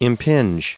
1599_impinge.ogg